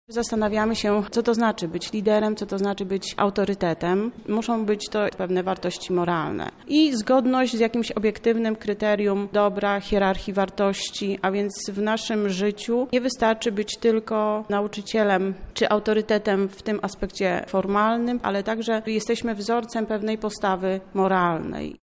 O sympozjum mówi jedna z organizatorek